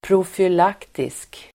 Ladda ner uttalet
profylaktisk adjektiv, prophylactic Uttal: [profyl'ak:tisk] Böjningar: profylaktiskt, profylaktiska Synonymer: förebyggande, skyddsåtgärd Definition: förebyggande prophylactic adjektiv, profylaktisk Förklaring: förebyggande
profylaktisk.mp3